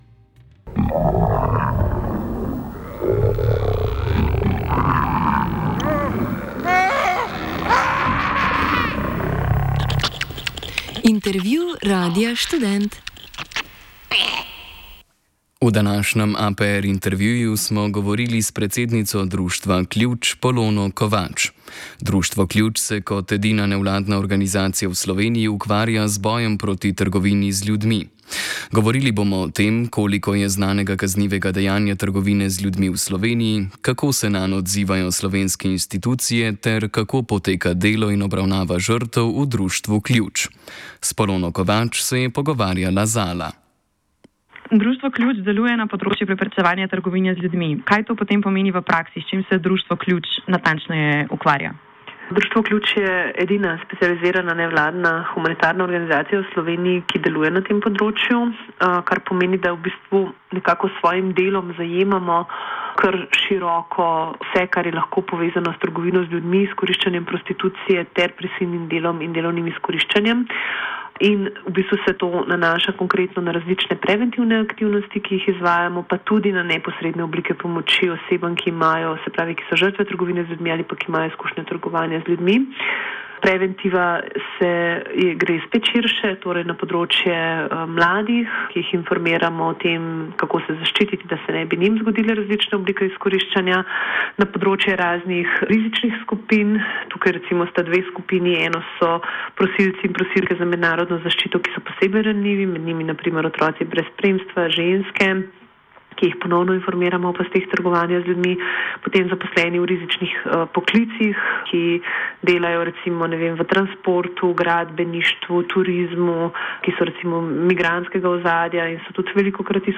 APR intervju
Intervjuvamo politike, gospodarstvenike in komentatorje.